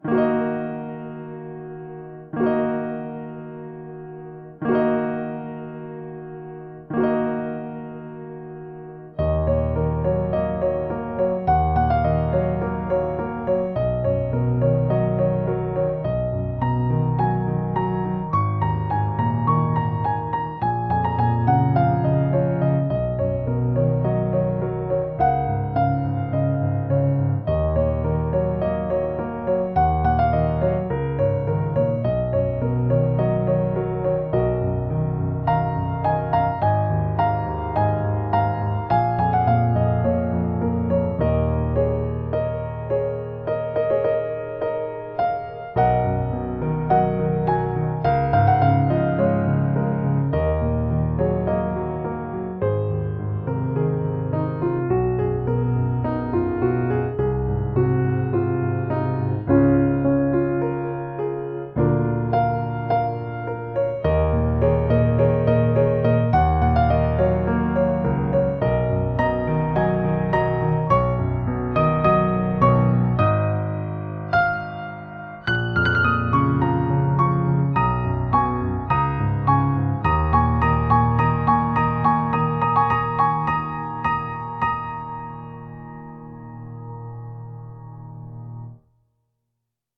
生ピアノ（生演奏） シリアス レトロ